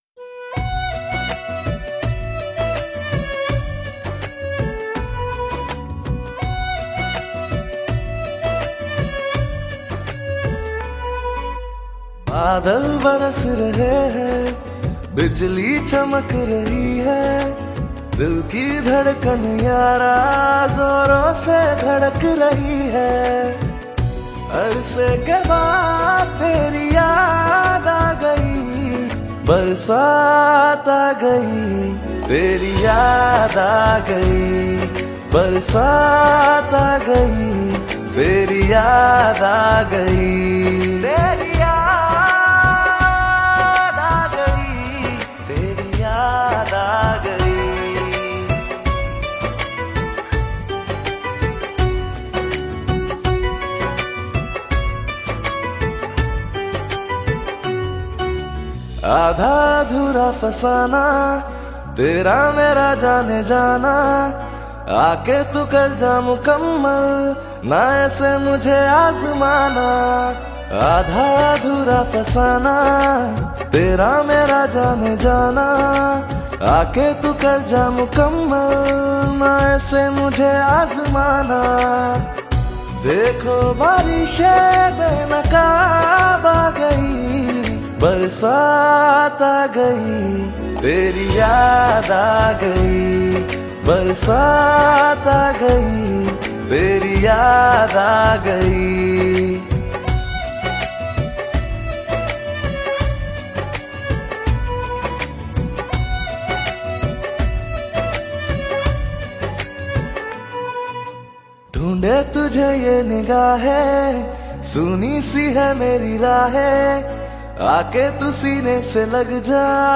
Hindi Pop